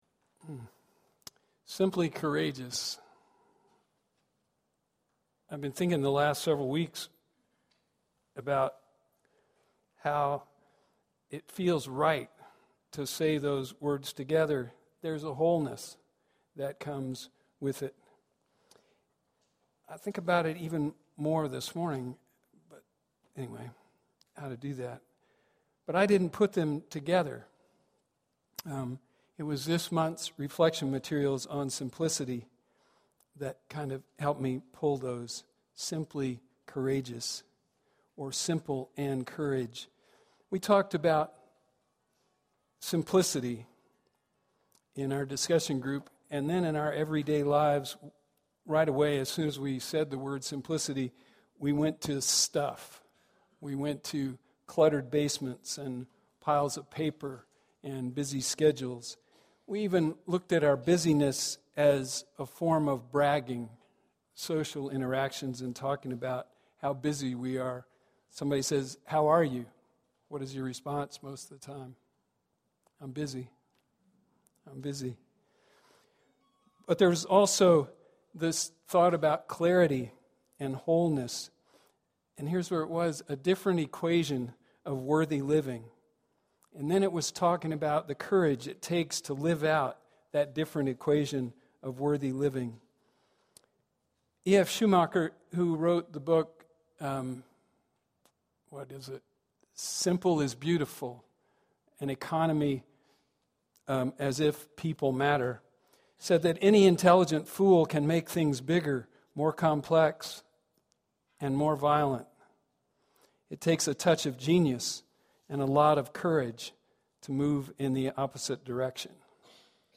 It is the hard work and courage of the DREAMers that turns the scholarships into dreams made real and lives fulfilled. Join us as DREAMers share their stories, and we reach out this Outreach Sunday to do our part in keeping the DREAM alive.